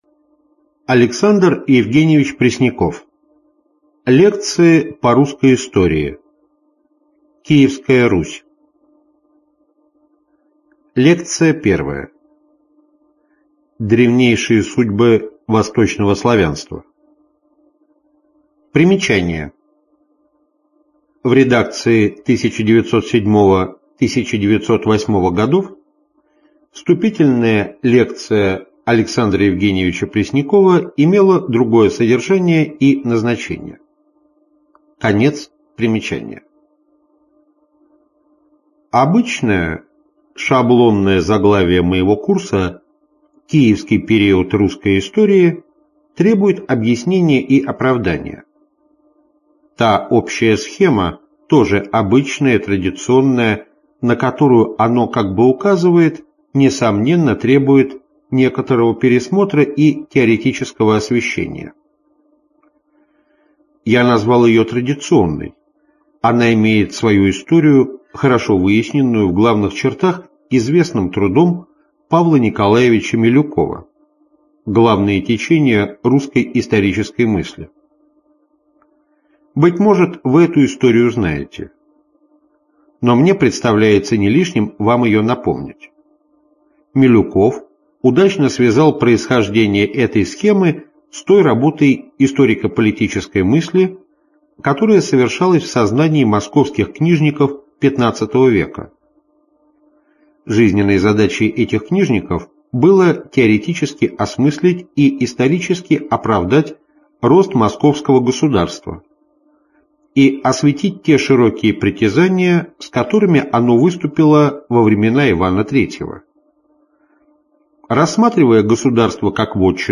Аудиокнига Киевская Русь | Библиотека аудиокниг